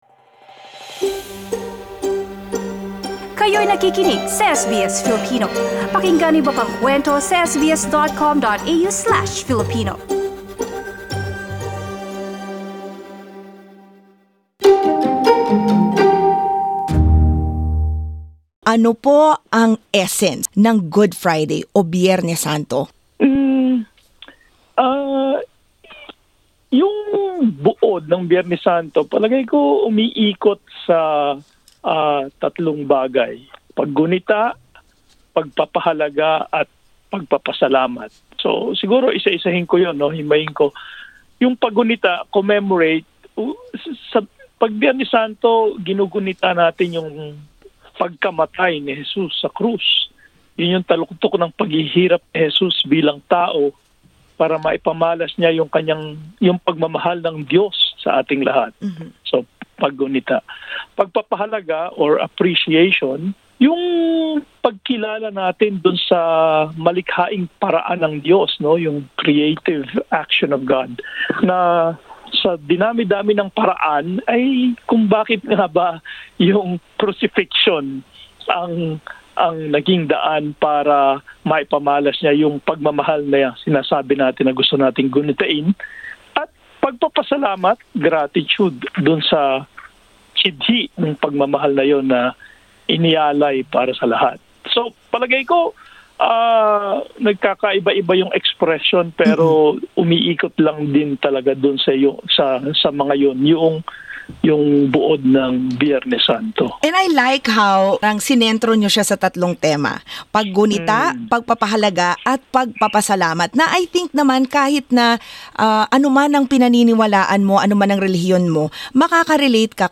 Aniya, ang mahalaga ay ang pagpapatibay ng pananampalataya sa paraan na angkop sa kakayahan ng bawat tao. Pakinggan ang panayam.